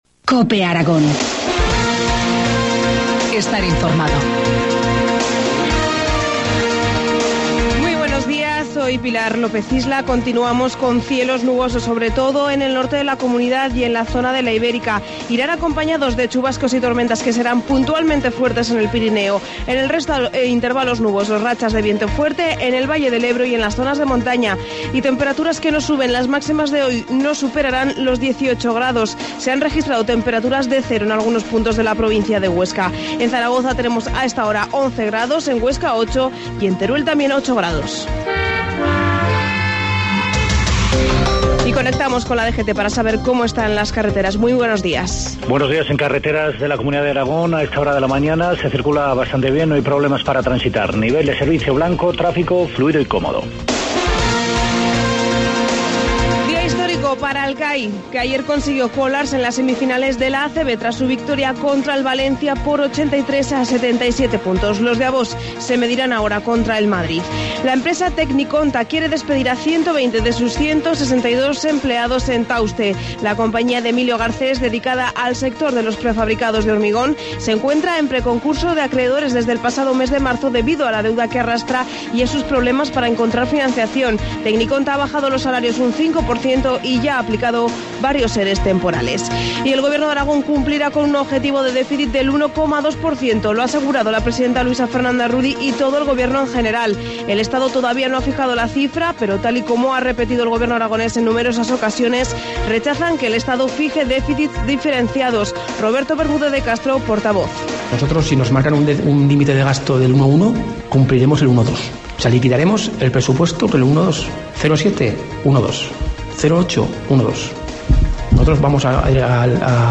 Informativo matinal, miércoles 29 de mayo, 7.25 horas